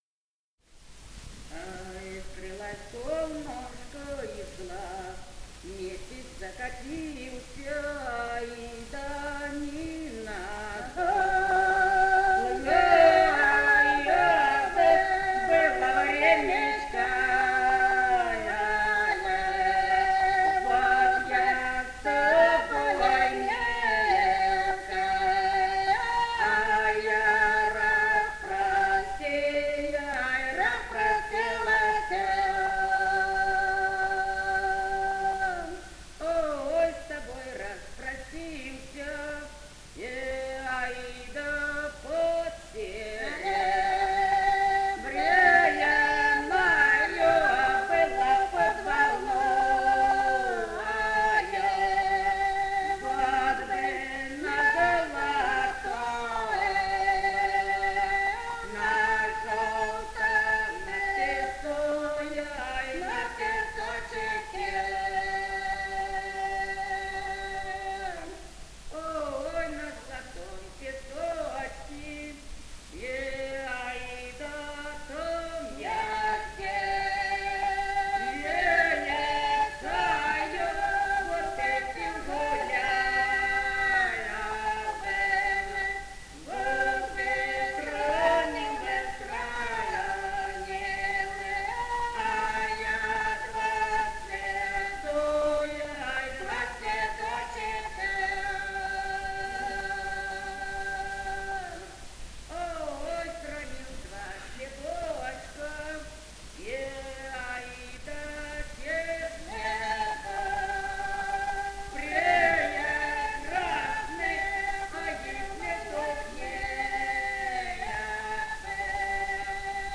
полевые материалы
женский дуэт
Ростовская область, ст. Вёшенская, 1966 г. И0939-06